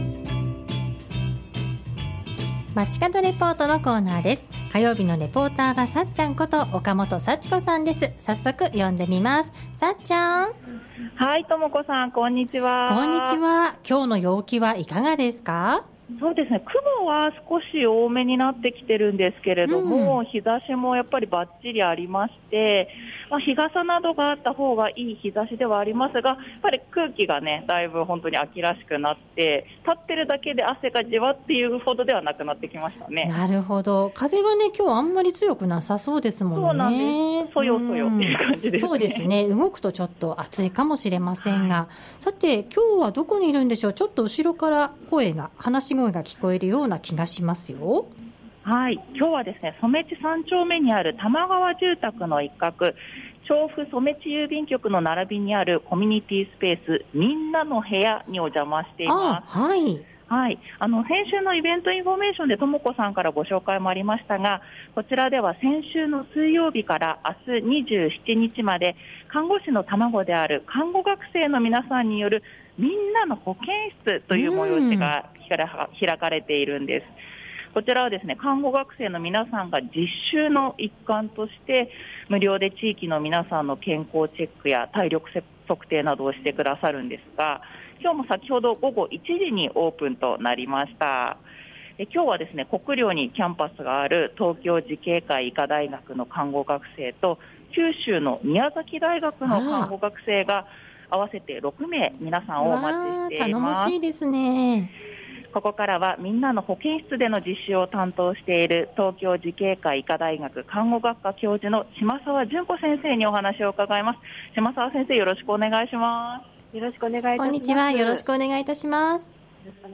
中継は染地3丁目の多摩川住宅で開催されている、看護師のたまごである看護学生のみなさんによる「みんなの保健室」の会場からお届けしました。
地域の特性や医療課題の違いを各地の実習を通して学んでいるそうです。 放送では実際に参加している学生にお話を伺っているのでぜひ、上記にアップした放送をお聴きください。